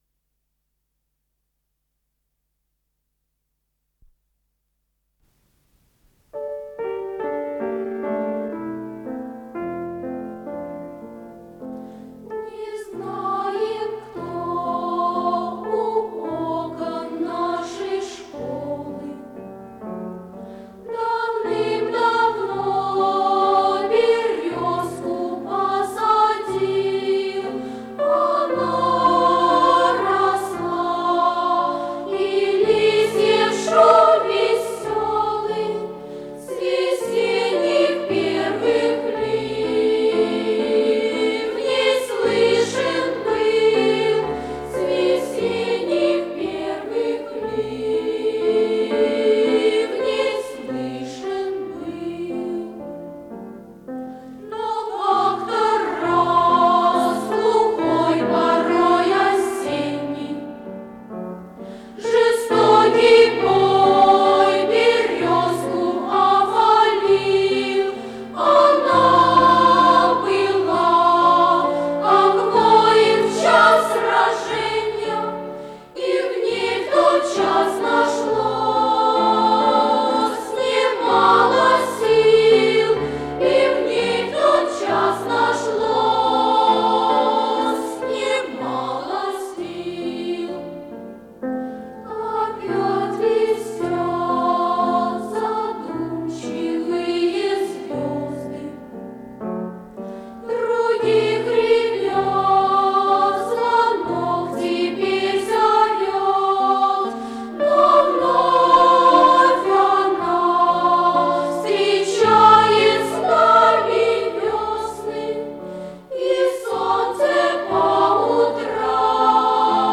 с профессиональной магнитной ленты
фортепиано